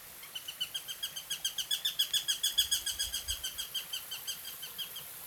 Gabián común
Accipiter nisus
Canto